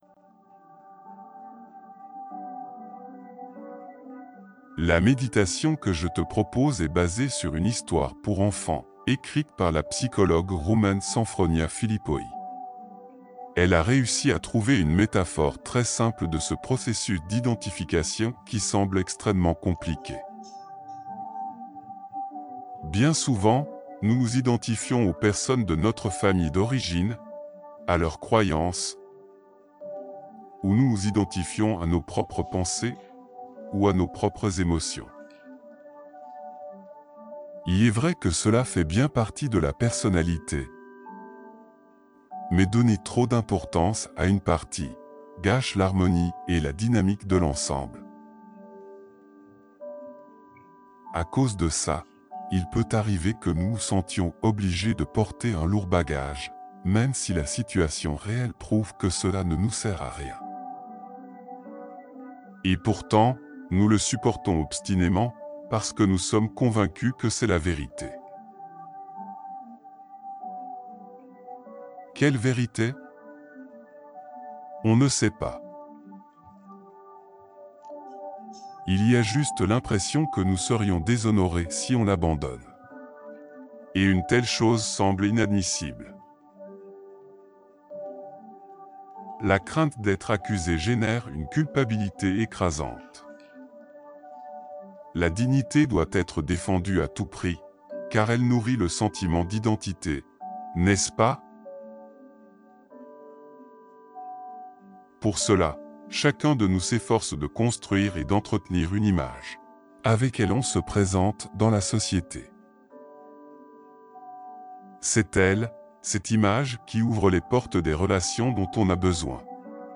De toute façon, même le texte sérieux est transmis dans un rythme hypnotique, produisant la relaxation dont votre subconscient a besoin pour effectuer le déclic du changement pendant l’histoire métaphorique.